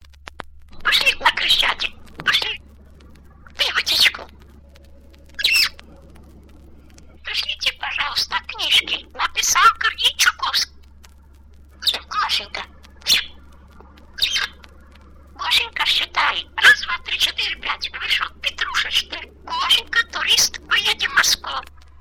Звуки попугая
Попугай говорит по-русски